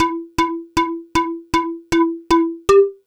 Track 11 - Muted Flute.wav